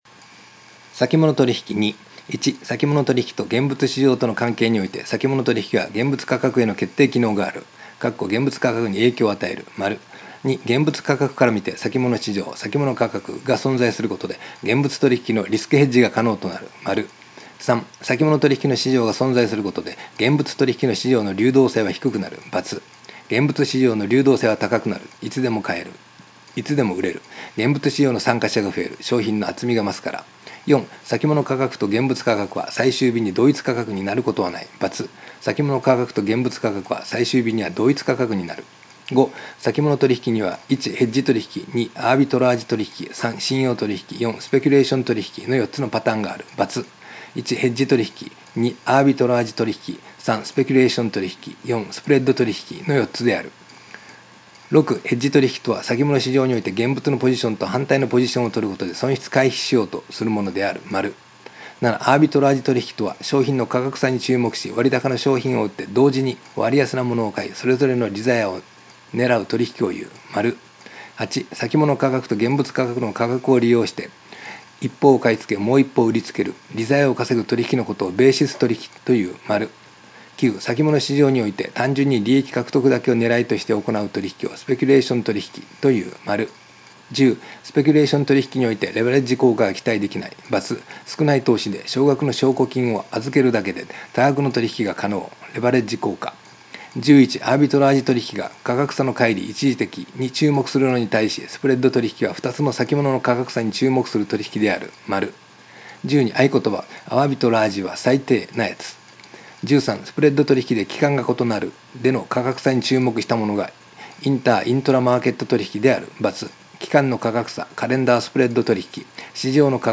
（中年オヤジのダミ声での録音ですが、耳から聴いて覚えたいという方はご活用ください）